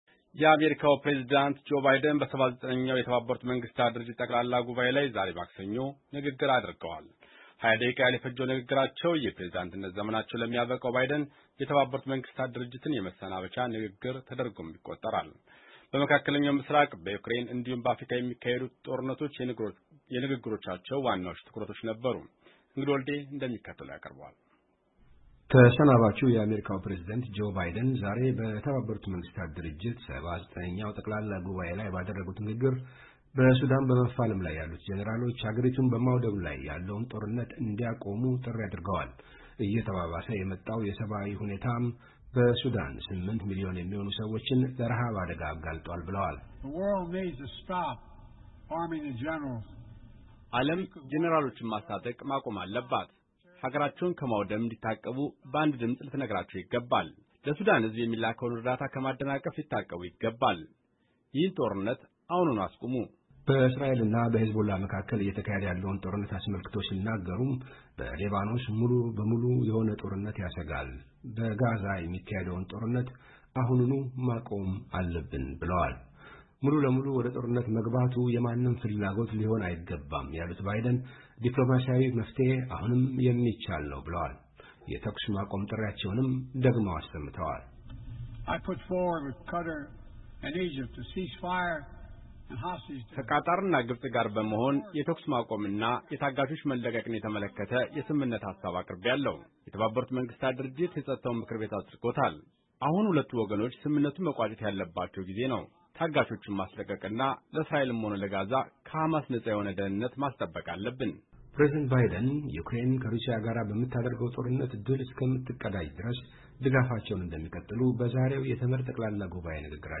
የአሜሪካው ፕሬዝደንት ጆ ባይደን በ79ኛው የተባበሩት መንግስታት ድርጀት ጠቅላላ ጉባኤ ላይ ዛሬ ማክሰኞ ንግግር አድርገዋል።